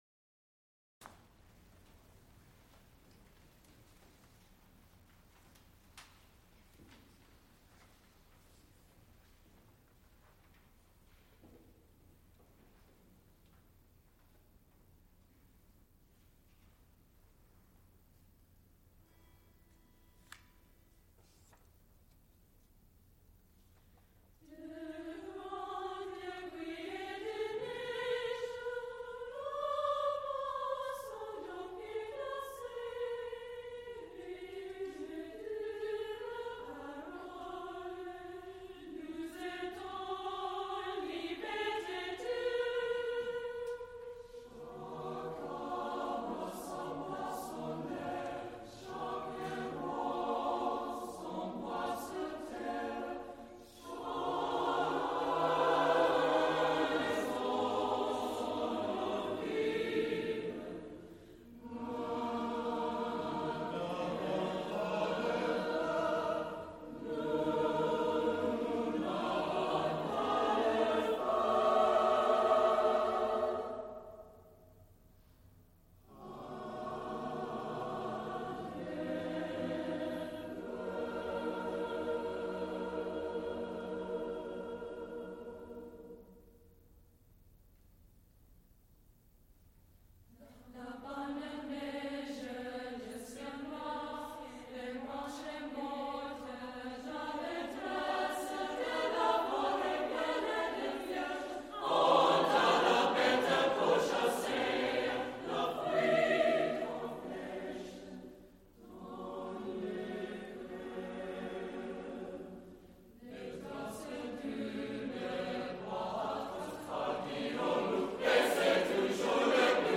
mezzo-sporano
conductor., Recorded live
Extent 2 audiotape reels : analog, quarter track, 7 1/2 ips ; 12 in.
Genre musical performances
Sacred (Mixed voices), Unaccompanied